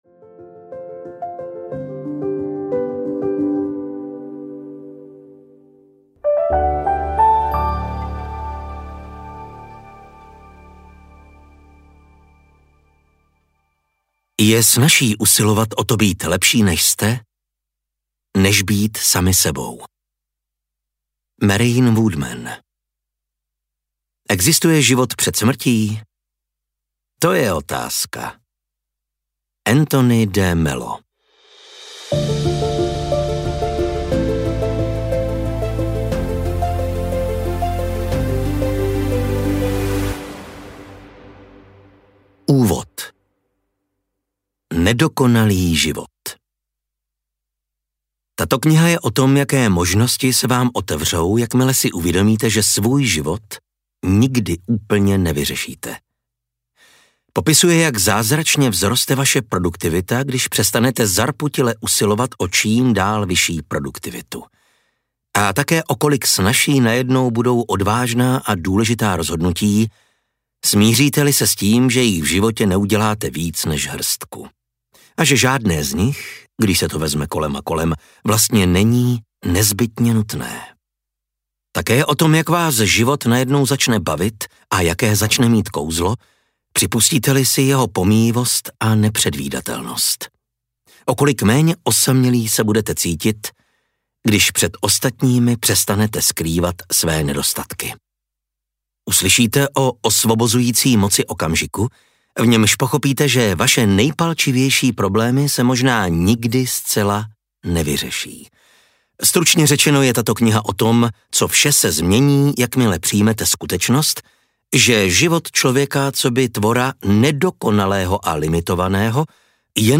Meditace pro smrtelníky audiokniha
Ukázka z knihy